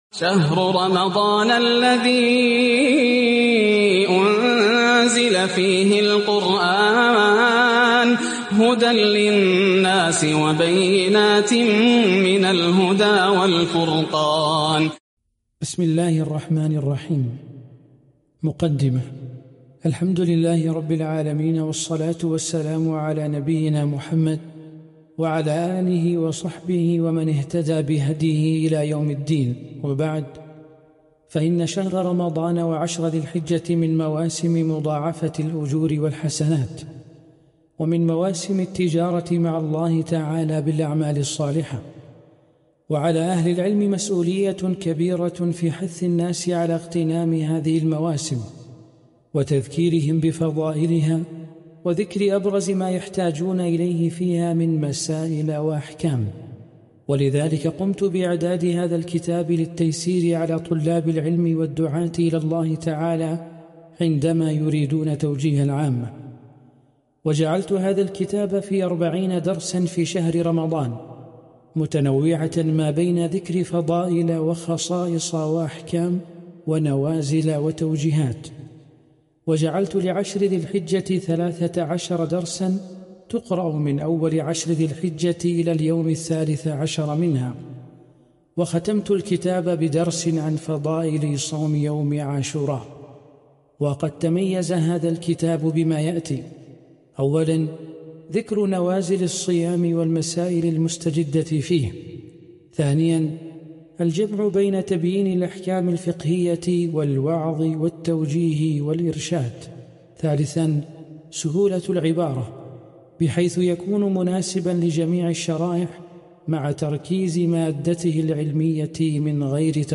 عنوان المادة المقدمة - القراءة الصوتية لكتاب عقود الجمان في دروس شهر رمضان ح١